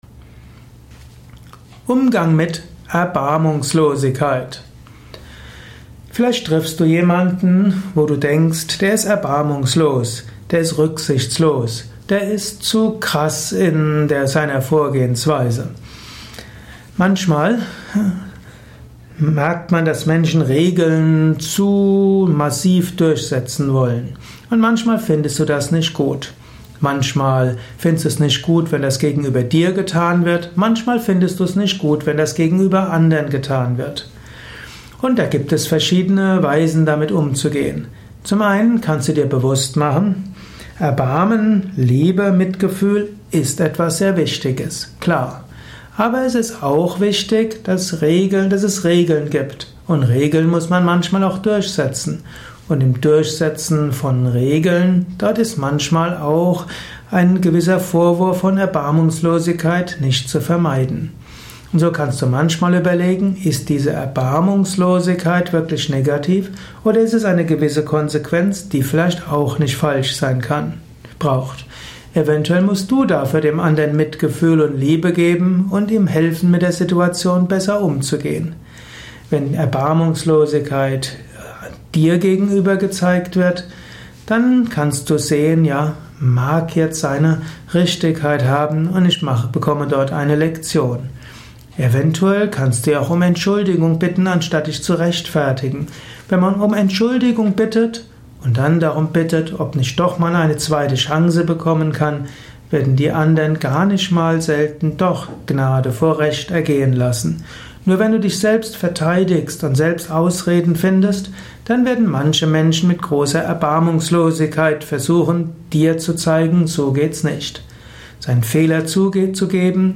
Eine Abhandlung zum Thema Erbarmungslosigkeit bei anderen. Erfahre einiges über Erbarmungslosigkeit durch dieses kleine kurze Vortragsaudio.